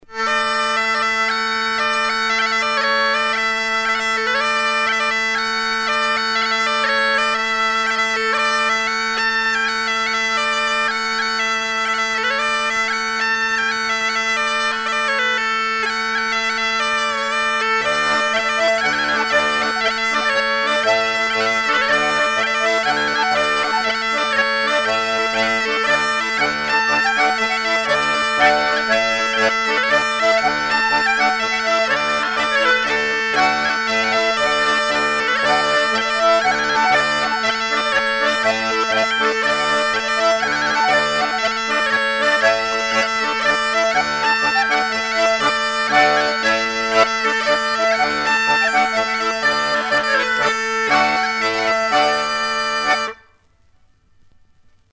gestuel : à marcher
Genre laisse
Catégorie Pièce musicale éditée